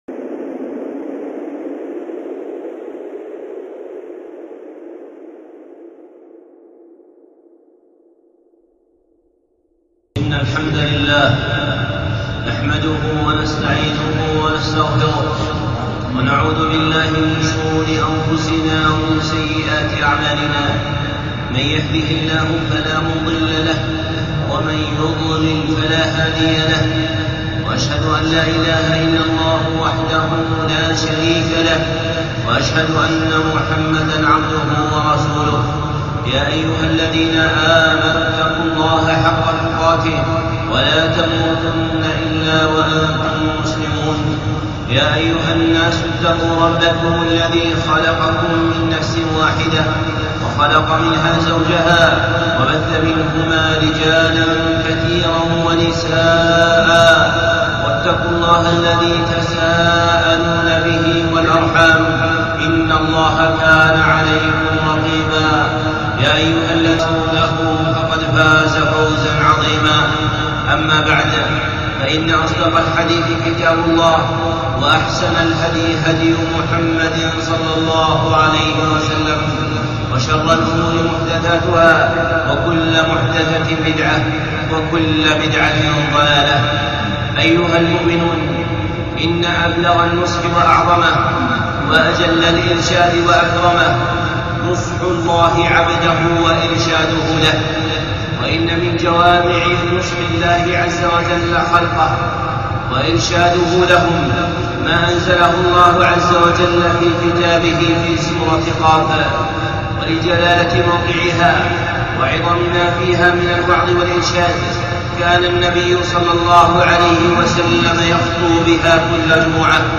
خطبة (نصح الله لعباده في سورة ق) الشيخ صالح العصيمي